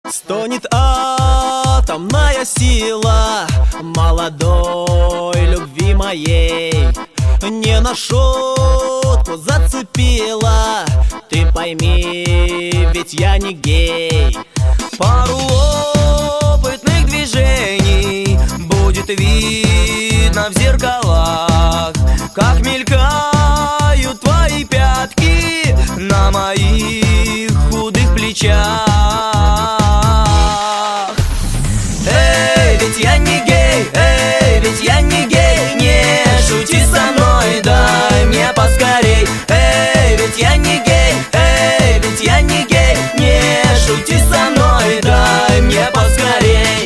• Качество: 128, Stereo
Гармошка